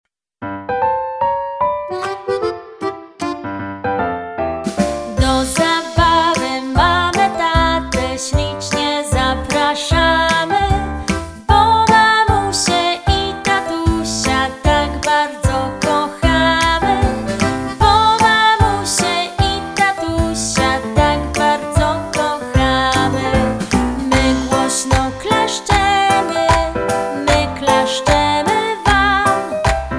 utwór w wersji wokalnej